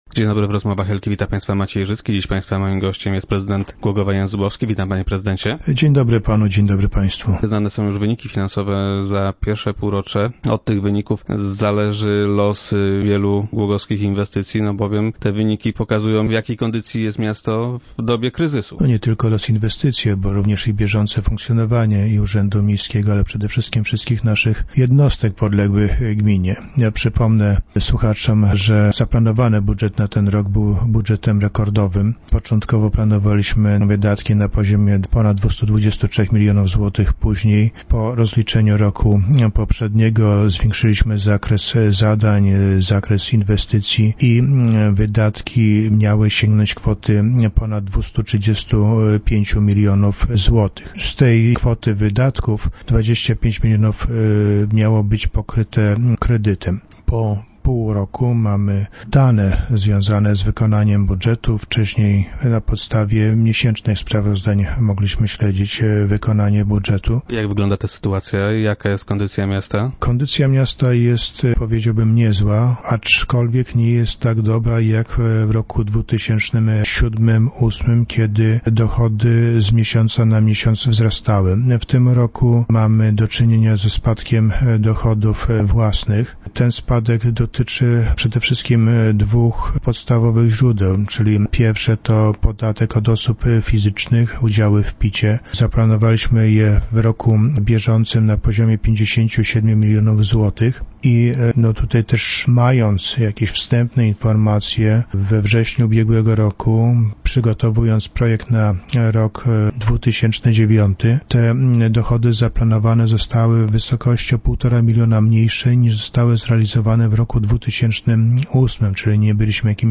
- Nie jest źle, ale bywało lepiej - twierdzi prezydent Jan Zubowski, który był dziś gościem Rozmów Elki.